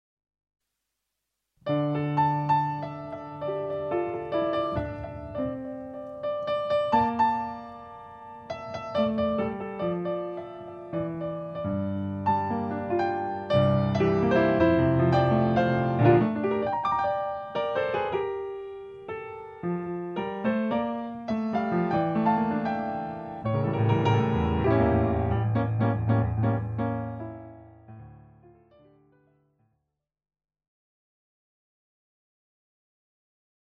pf solo